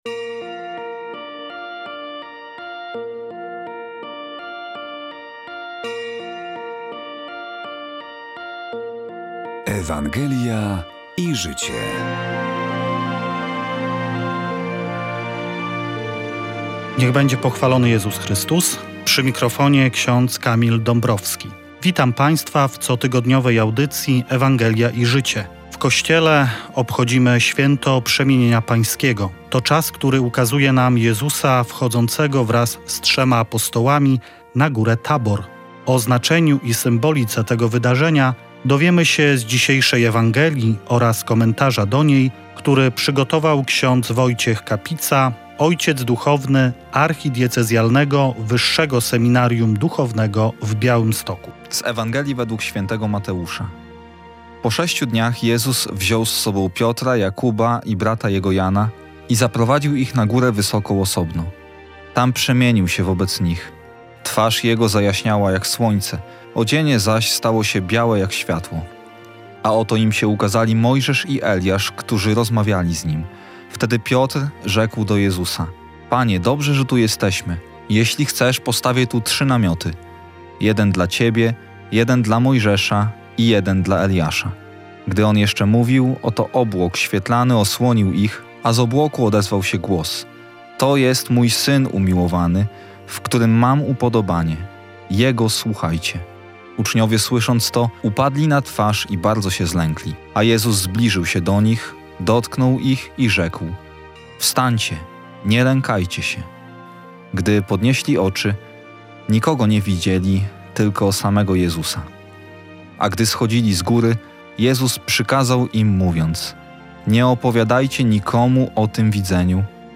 relacja z pieszej pielgrzymki do Częstochowy
felieton ks. biskupa Tadeusza Bronakowskiego w związku z miesiącem